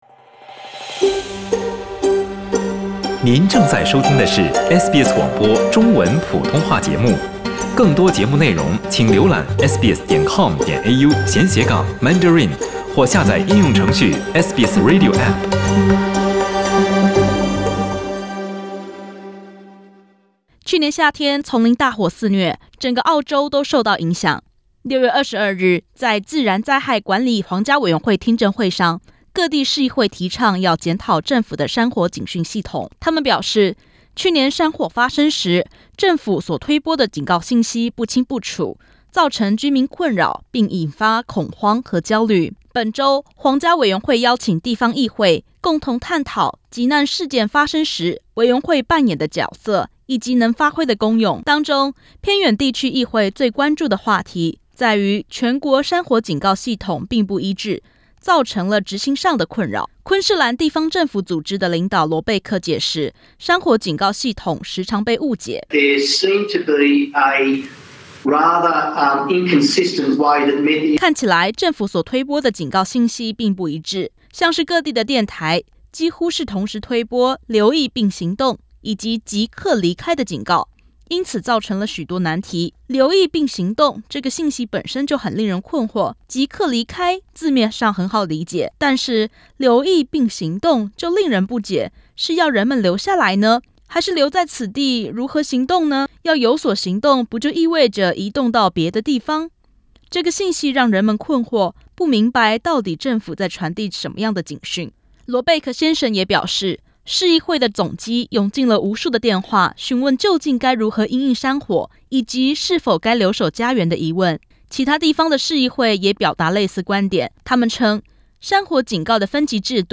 许多人向自然灾害管理皇家委员会反应，对政府发送的山火警讯感到困惑，尤其不能理解警讯的分级标准。点击上图收听录音报道。